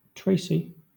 Ääntäminen
Vaihtoehtoiset kirjoitusmuodot Tracy Ääntäminen Southern England Tuntematon aksentti: IPA : /ˈtɹeɪsi/ Haettu sana löytyi näillä lähdekielillä: englanti Käännöksiä ei löytynyt valitulle kohdekielelle.